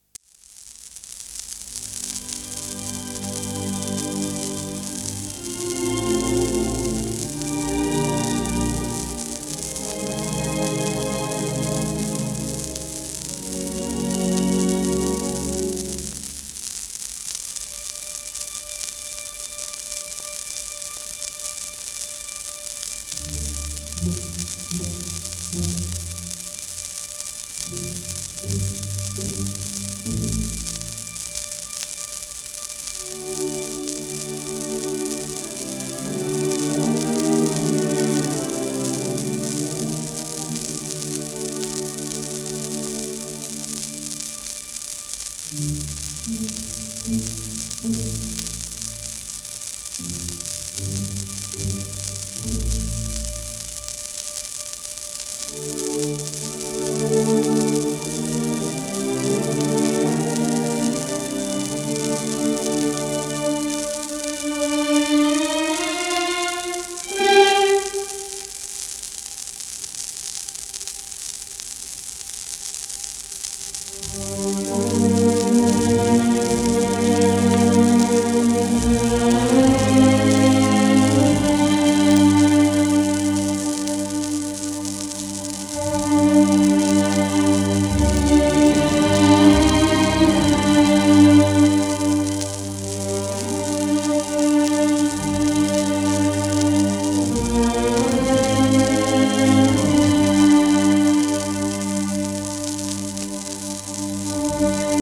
1940年代後半ごろの録音